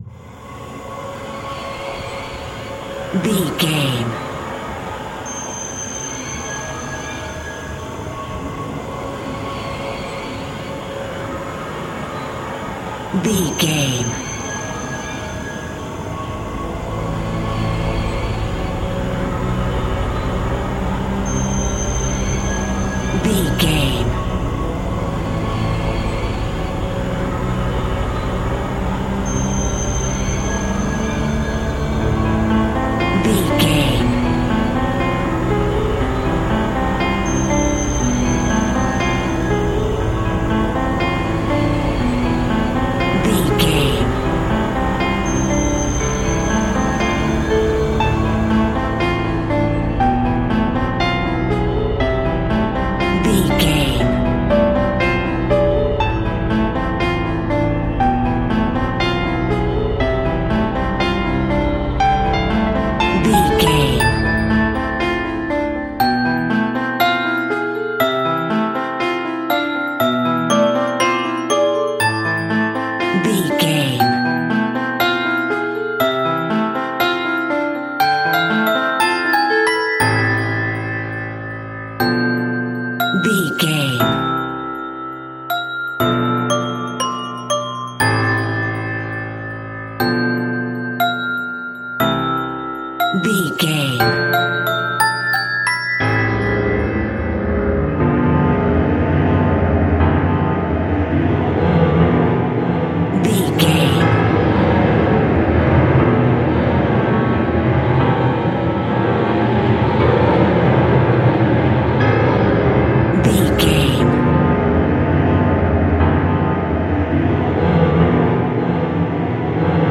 In-crescendo
Thriller
Aeolian/Minor
F#
Slow
scary
tension
ominous
dark
suspense
haunting
eerie
industrial
synthesiser
piano
ambience
pads
eletronic